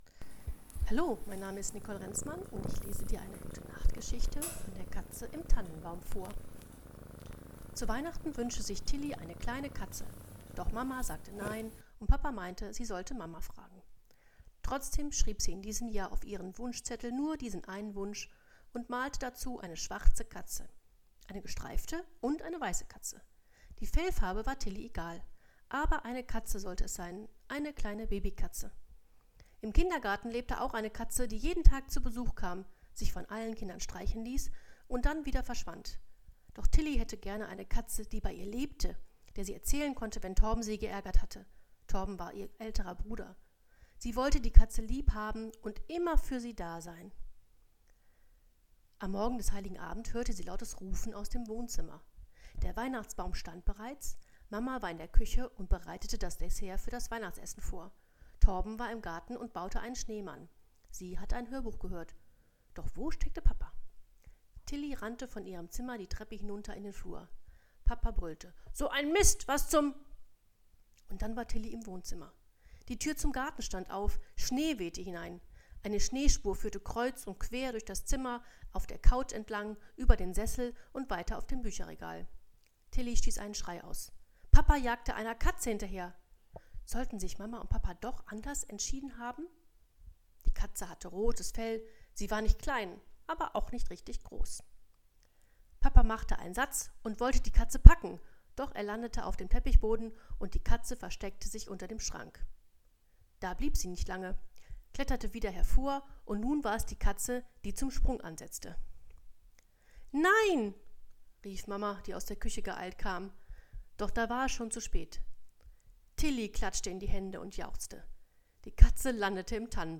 Ähnliche Beiträge Gute-Nacht-Geschichte
Vorlesen